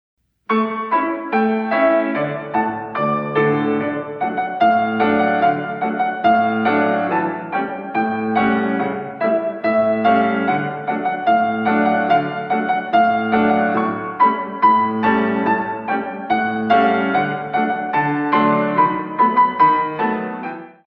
In 2
64 Counts